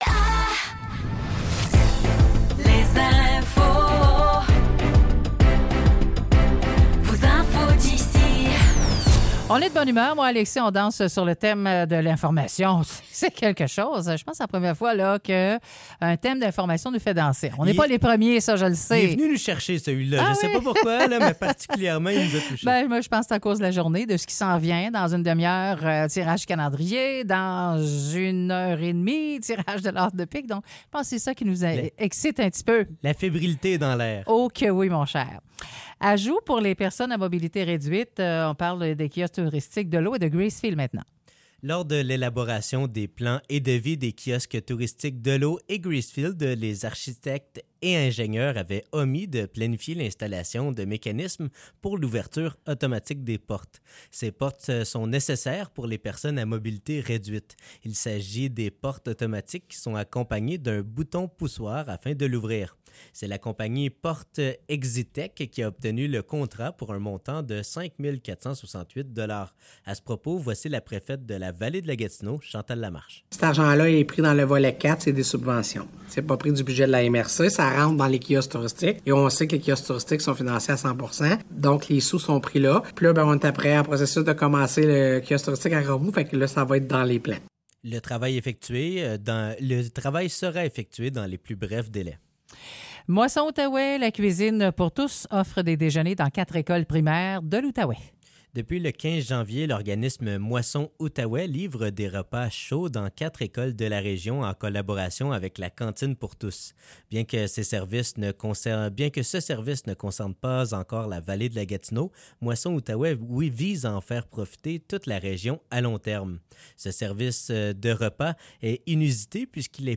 Nouvelles locales - 1er février 2024 - 10 h